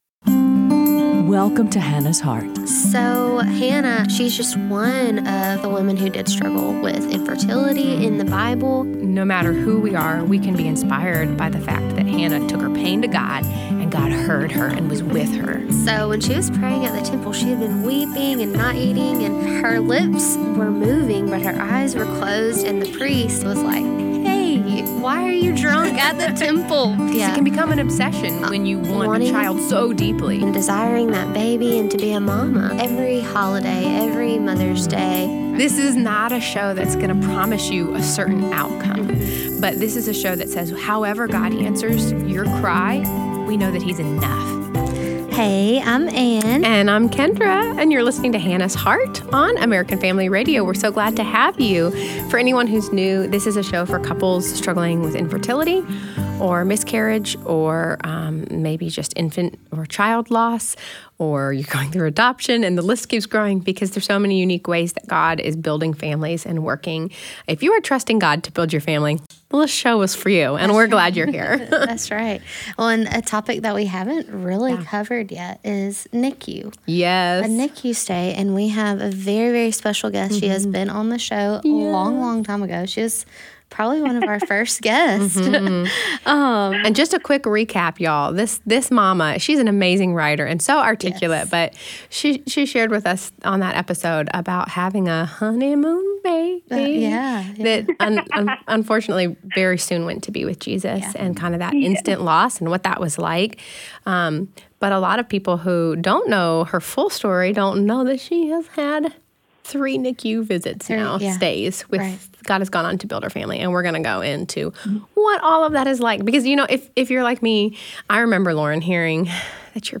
This episode was recorded live from the NICU